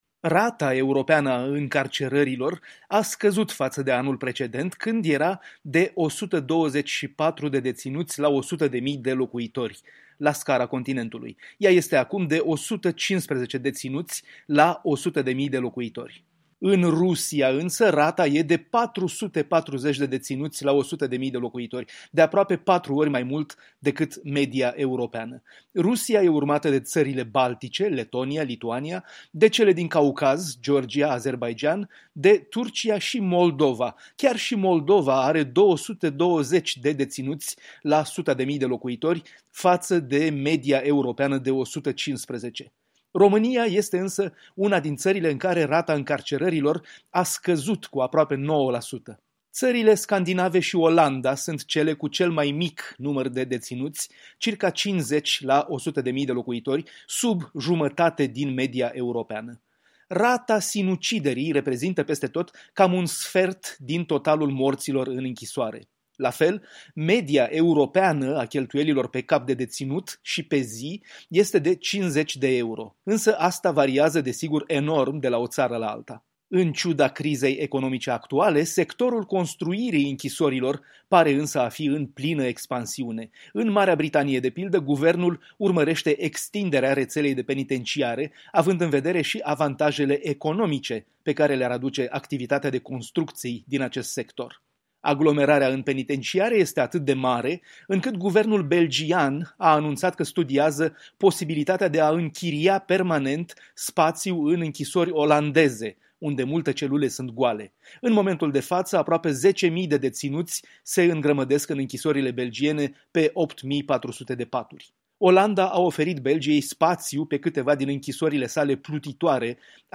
Corespondența zilei de la Bruxelles